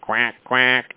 quack.mp3